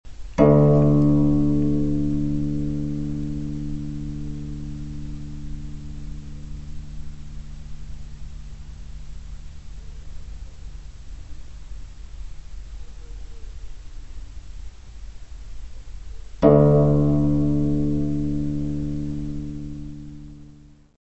: stereo; 12 cm
Área:  Novas Linguagens Musicais